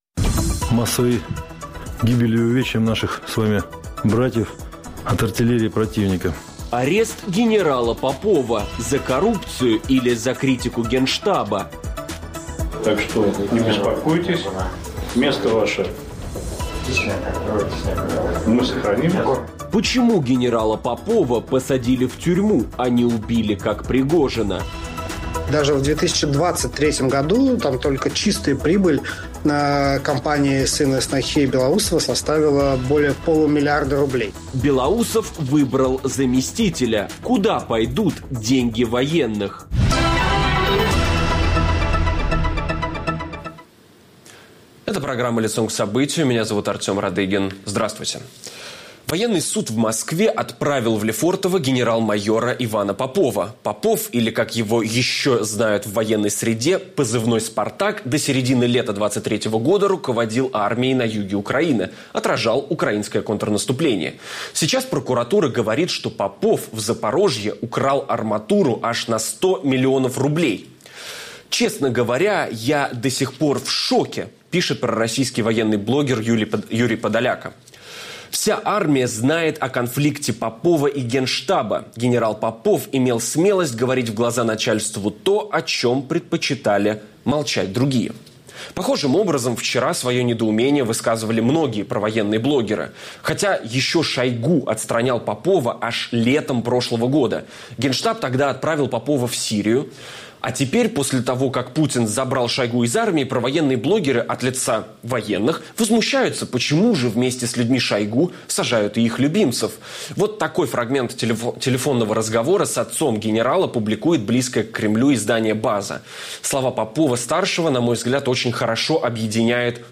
За что на самом деле мог быть арестован генерал Попов? И куда пойдут деньги военных при Белоусове? Обсуждаем с политологом Аббасом Галлямовым.